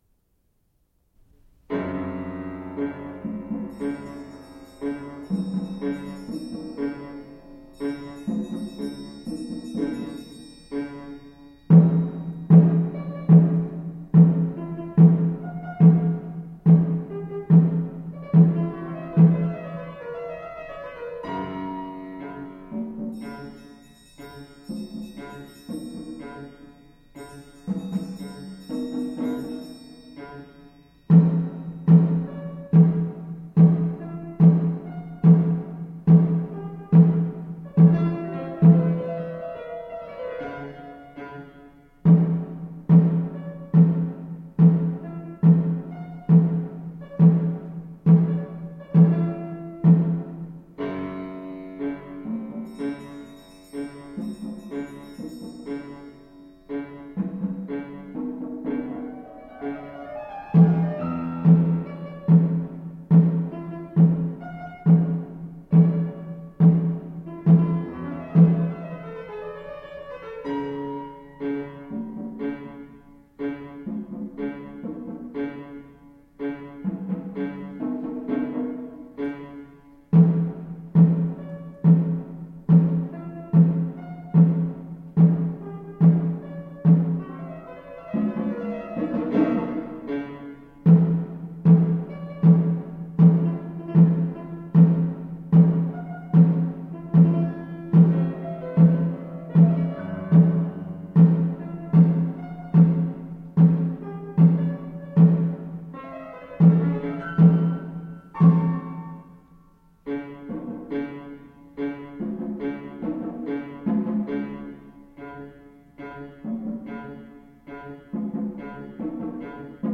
Genre: Percussion Ensemble
# of Players: 6